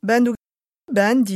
Moita Verde (nord)